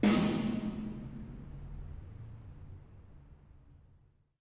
Note the signal to noise ratio of the original snare versus the artificial, and ignoring that, the similarity of the two reverbs.
Snare Sample recorded in the space.
snare1.mp3